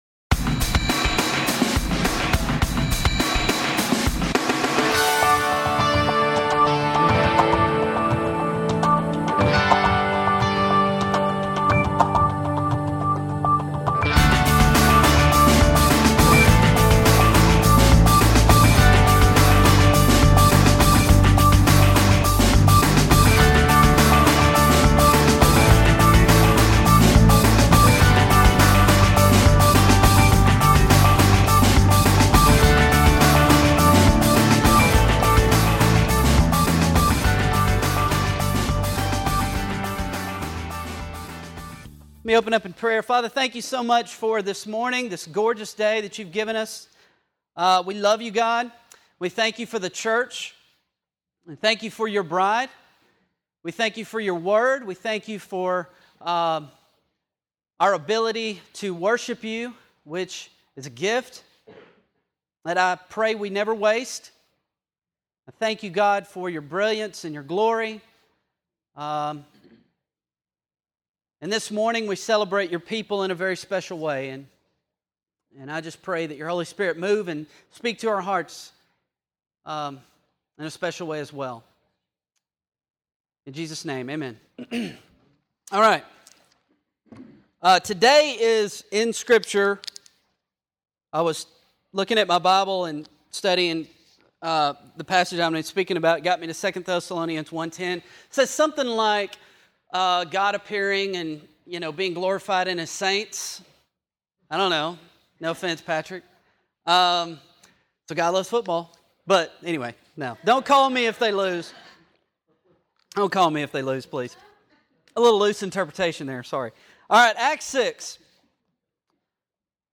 A message from the series "The Way."